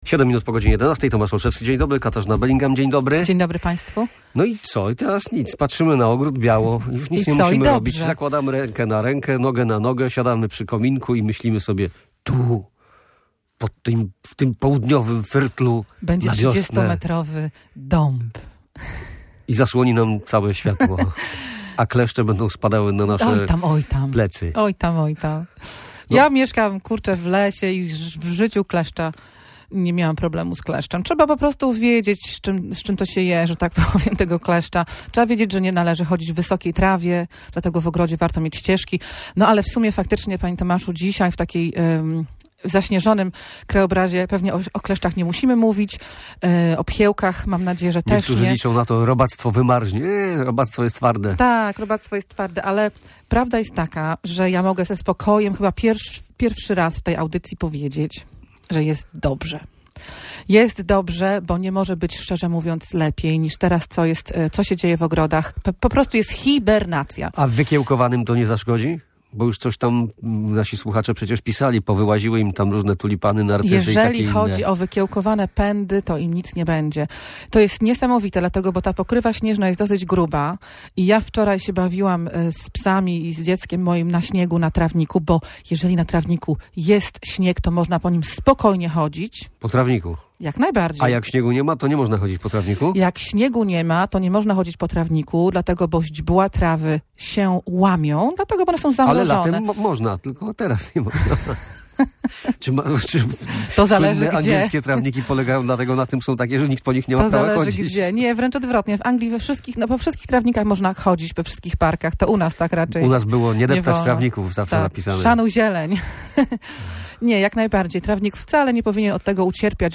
– tłumaczyła w Radiu Gdańsk